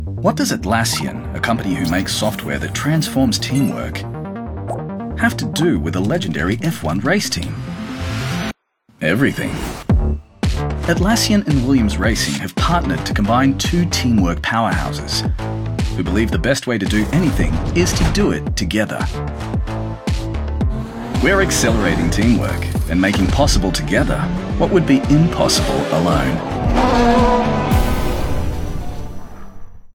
Male
Television Spots
Words that describe my voice are Deep, Tenor, Credible.